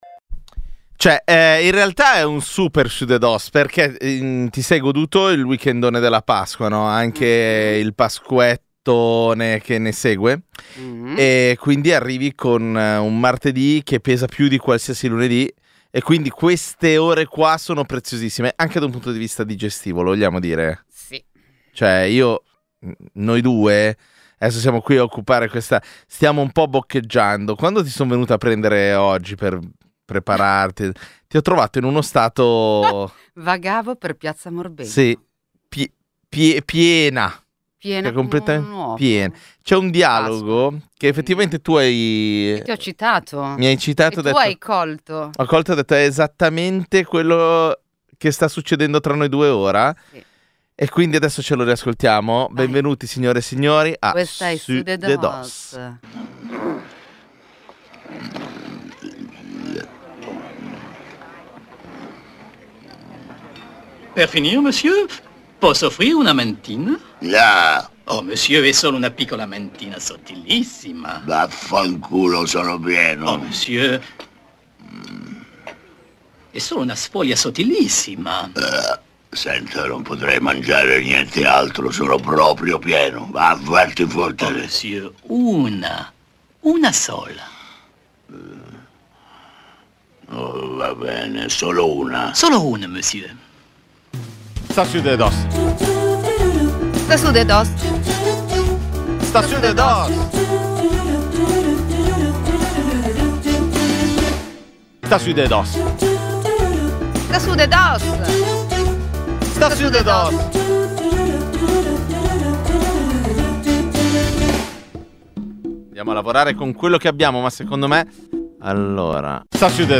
Sudedoss è il programma di infotainment che ogni domenica sera dalle 19.45 alle 21.30 accompagna le ascoltatrici e gli ascoltatori di Radio...